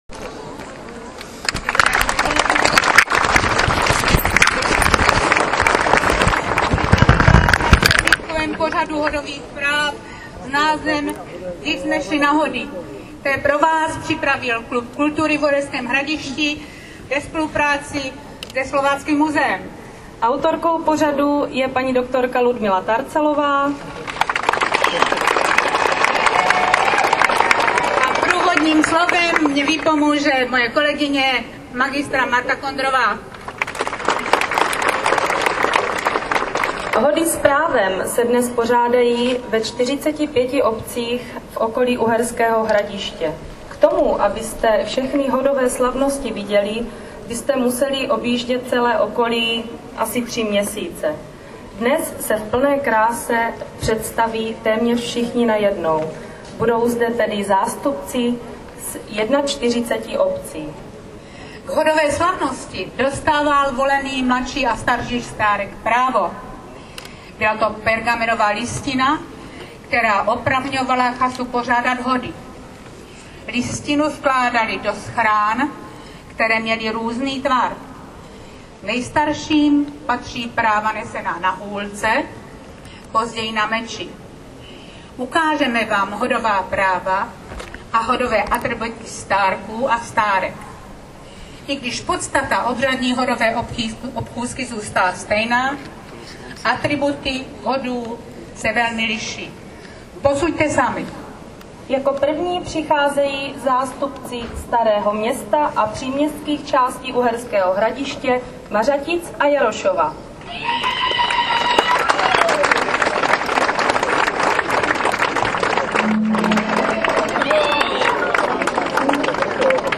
41 obcí dnes obrazně "defilovalo" před velkým množstvím diváků na Slováckých slavnostech vína v Uherském Hradišti. Pokud bych měl být přesný, divákům se ukázali stárky a stárci právě ze 41 obcí v krojích, které můžete vidět na hodech.